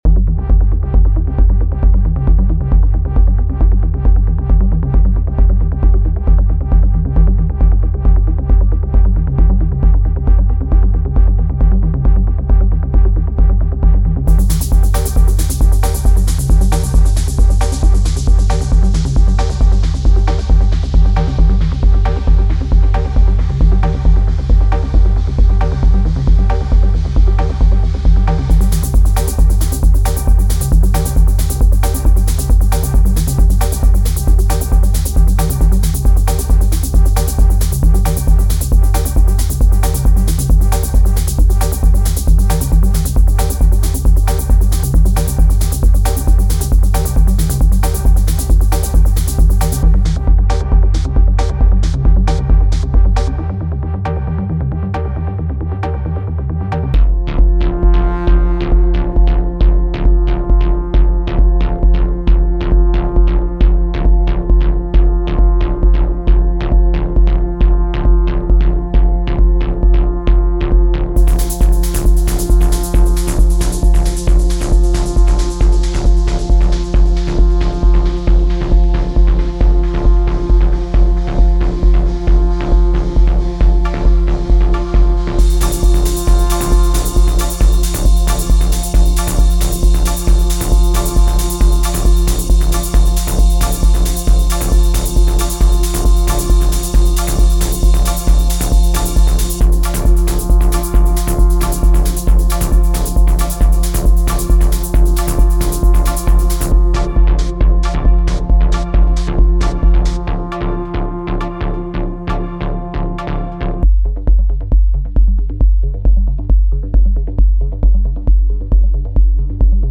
Melodic Techno Tech House Techno
“Melodic & Peak Time Tech for SPIRE” is a unique collection of presets designed to be easily adaptable in all shades of thech-house and techno, with a generous amount of 50 patches that cover Bass, Leads, Fx, Pads and Synth you will be sure to find the right sound you need!